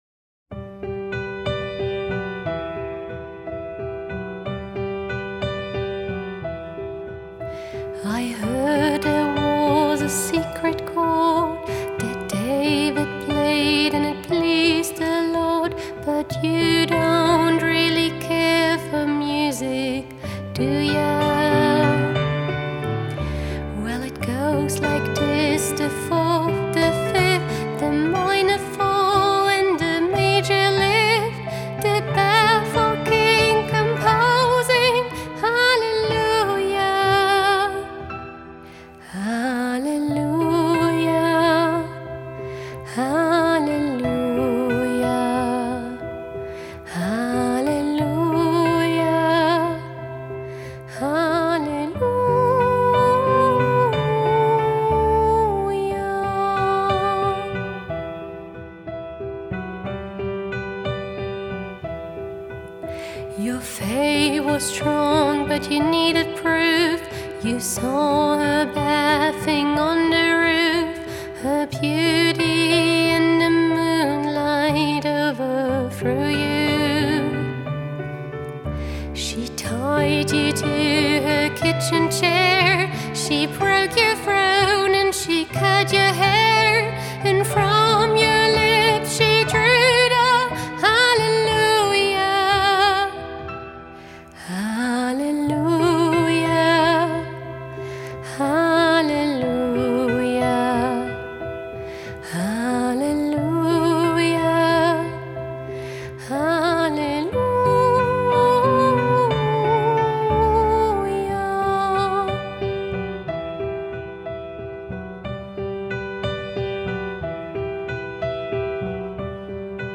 Pianobegleitung